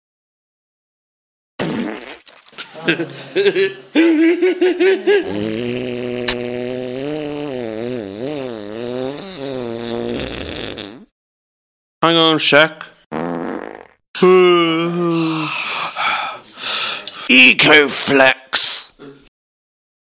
Contains wind